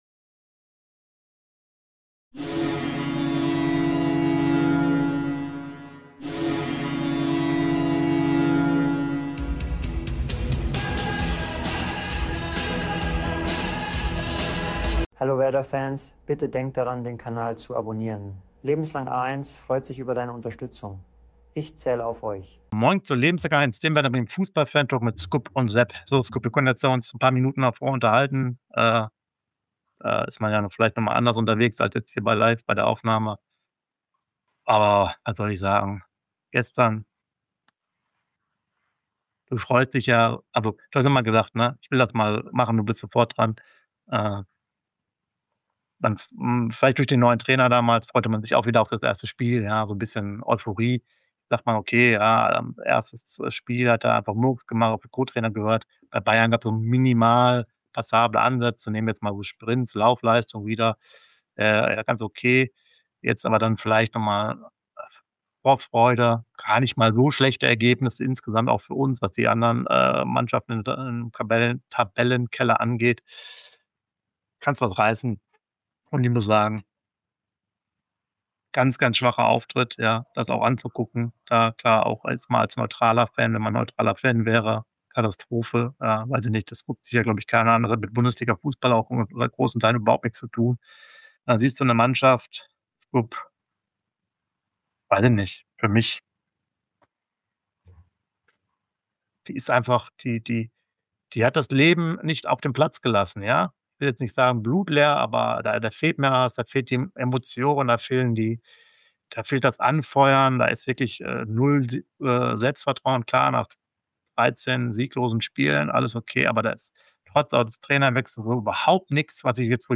Werder Bremen - News & Aktuelles 23.02.2026 | Nachbericht | St. Pauli vs. Werder | 2-1 ~ 1. Bundesliga Podcast